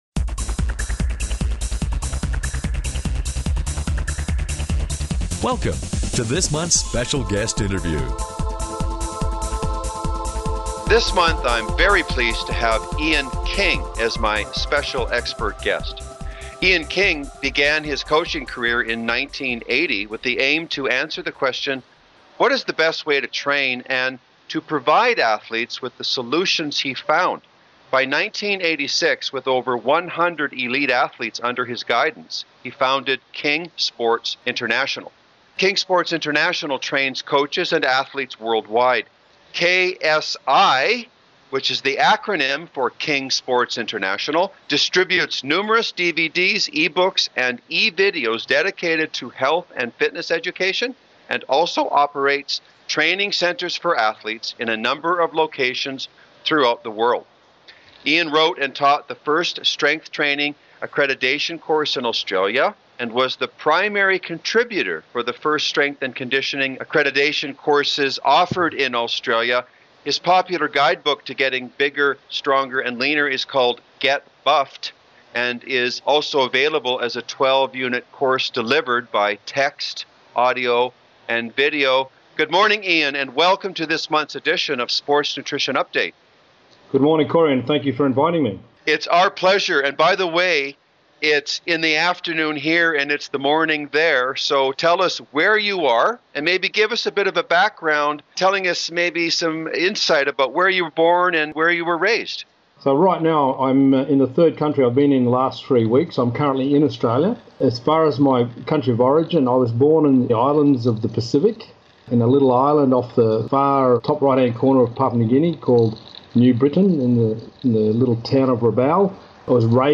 Special Guest Interview Volume 14 Number 2 V14N2c